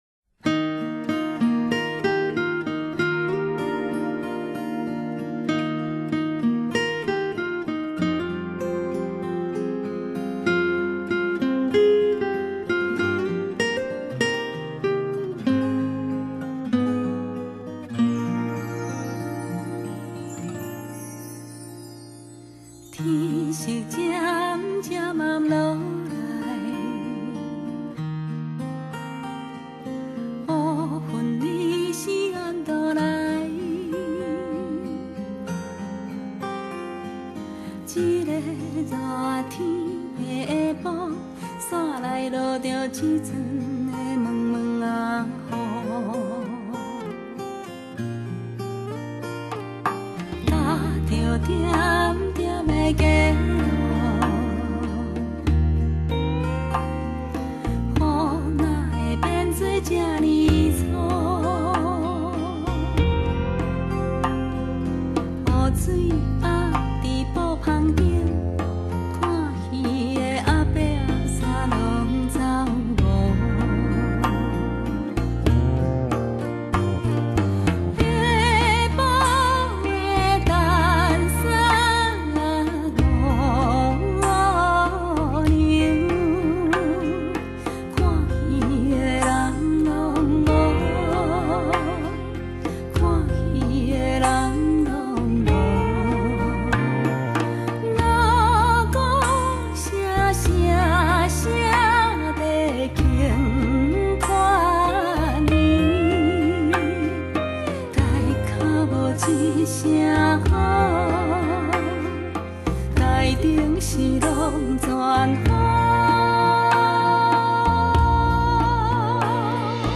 【台語天后】